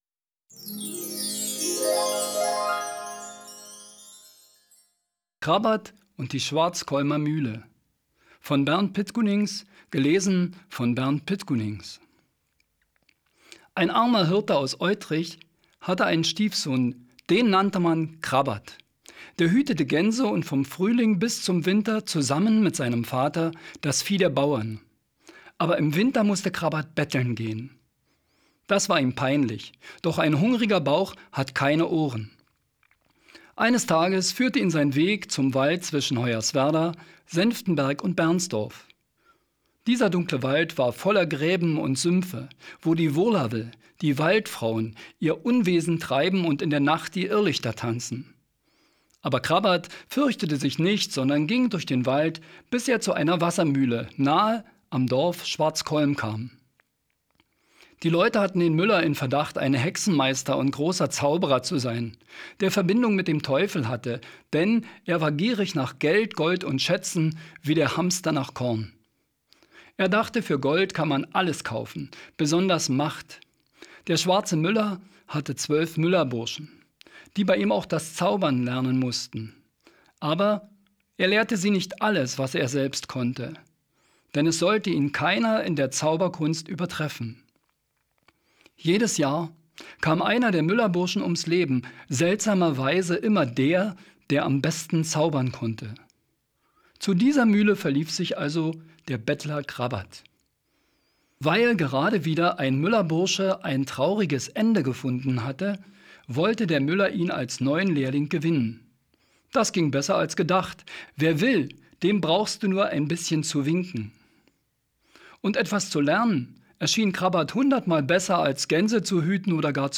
l24-muellistkeinesage-08-krabat-deutsch.wav